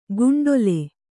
♪ guṇḍole